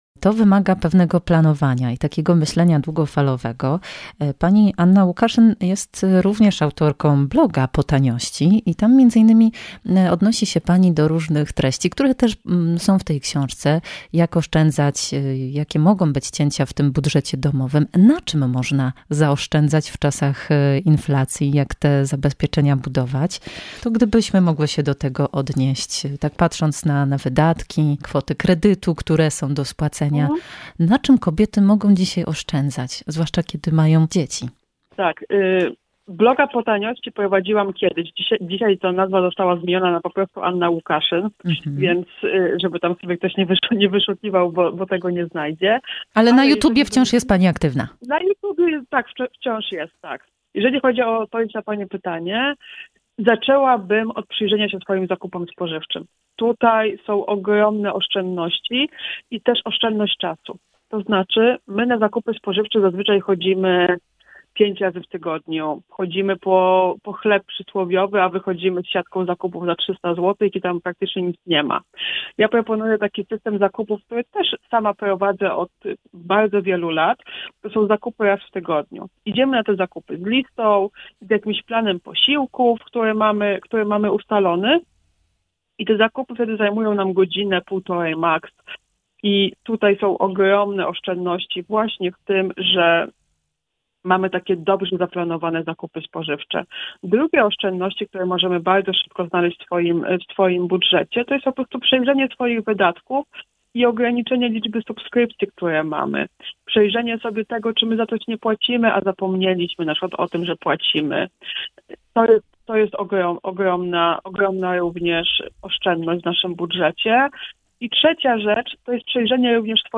Emisja wywiadu we wtorek 30 stycznia po godz. 16:10.